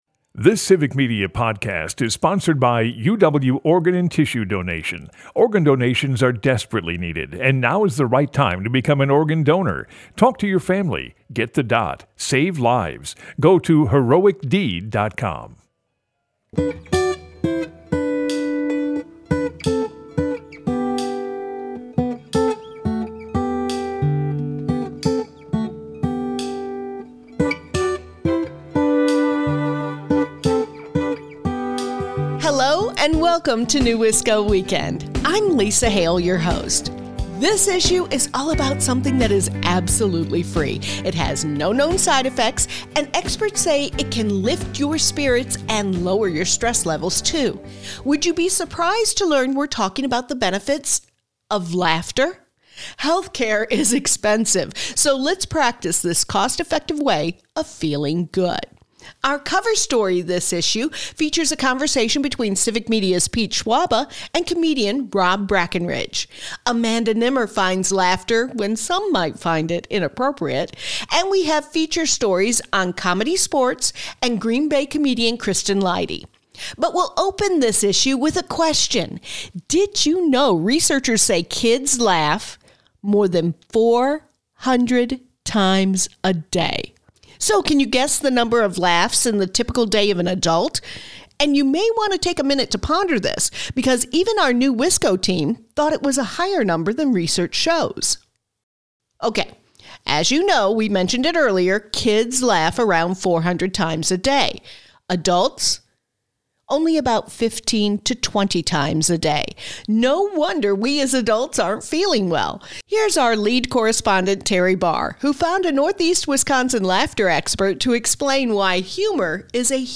NEWisco Weekend is a news magazine-style show filled with conversations and stories about issues, entertainment, and culture making the Fox Valley, Green Bay and beyond -- a rich, unique area to call home.